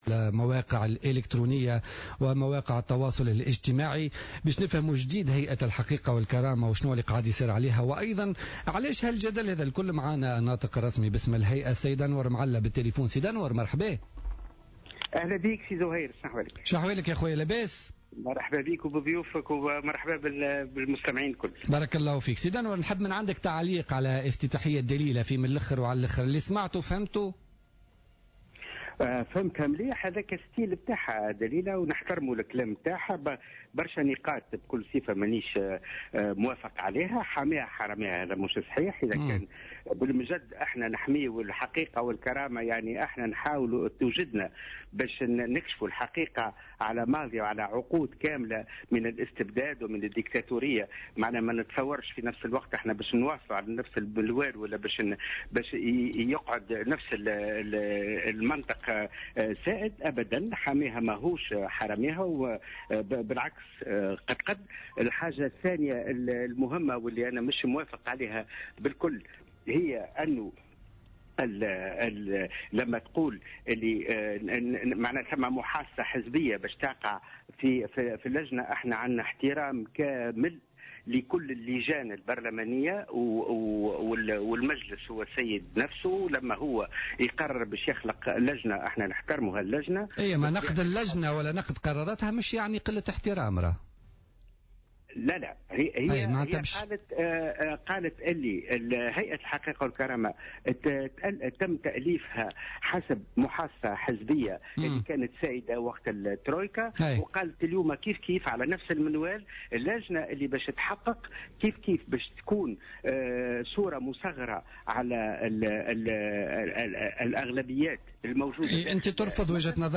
أكد أنور معلى عضو هيئة الحقيقة والكرامة في مداخلة له في بوليتيكا اليوم الخميس 10 سبتمبر 2015 أن الهيئة لها ثقة كاملة في اللجنة التي تكونت صلب مجلس النواب لمساءلتها مشيرا إلى ان الهيئة ليست فوق المحاسبة والمساءلة ومن حق دائرة المحاسبات أن تنظر في حساباتها من منطلق الشفافية على حد قوله.